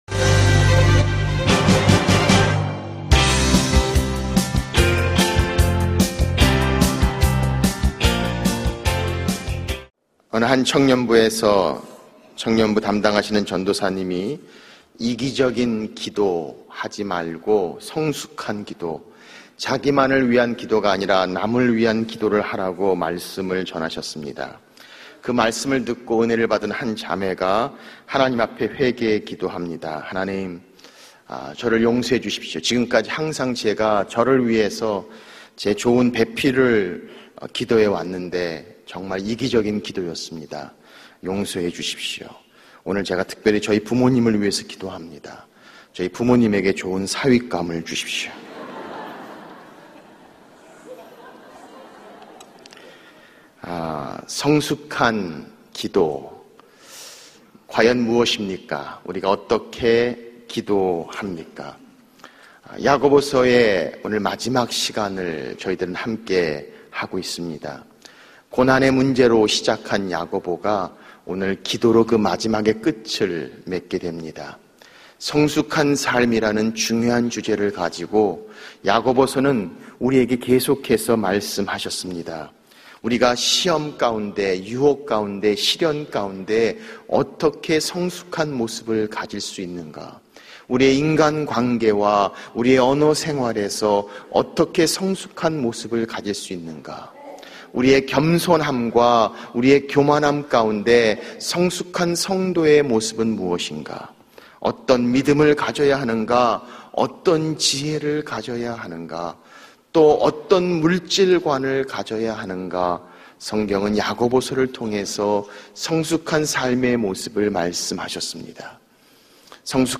설교 | (14) 기도도 성숙한 기도가 있습니까?